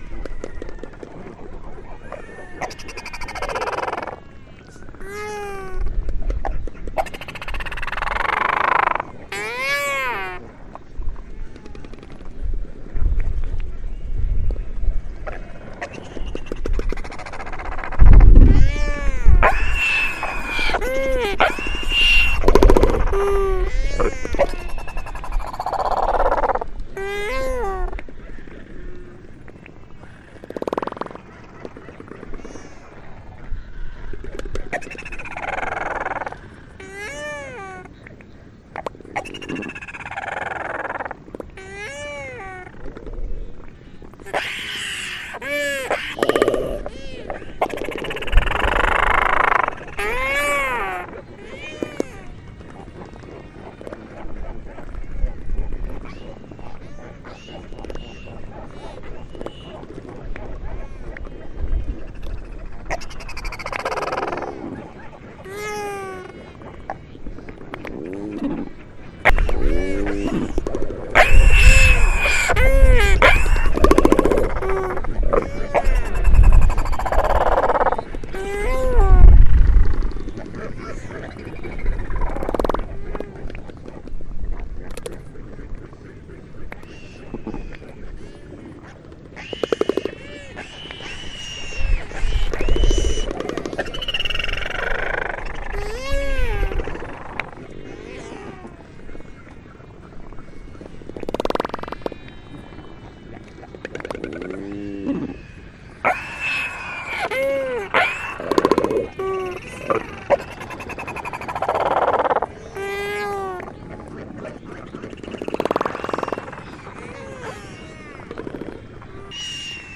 Albatros real del norte.wav